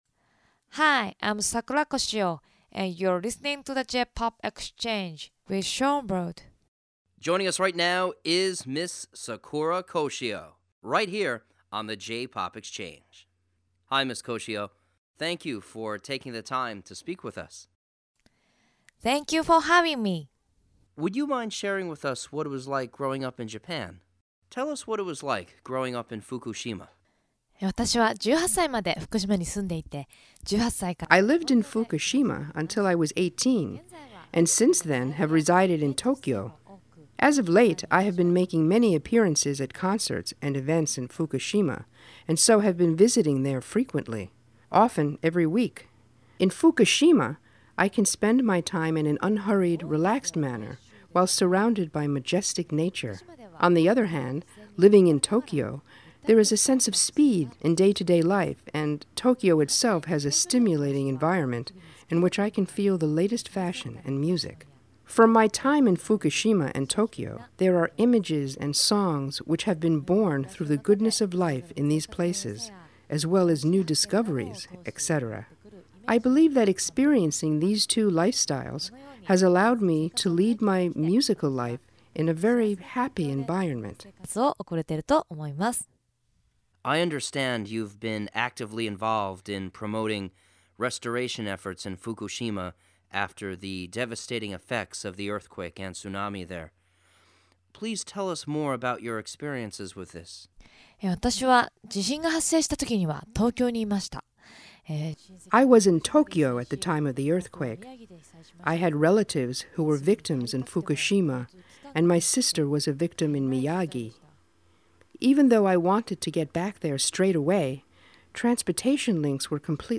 Radio Interview